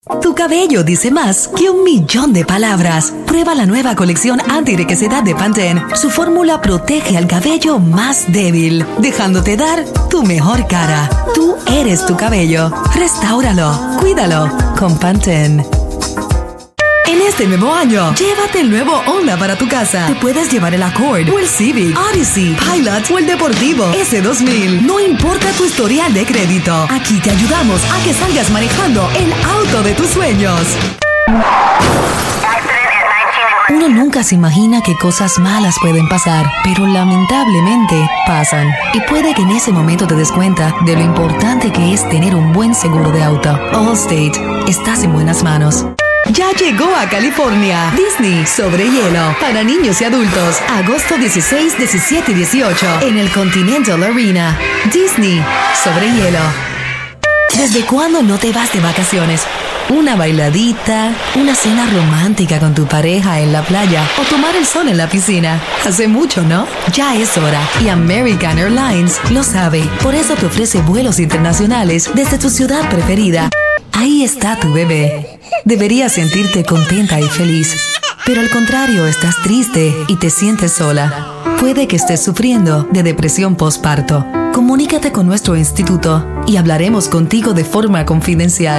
İspanyolca Seslendirme | İspanyolca Dublaj | BiberSA Prodüksiyon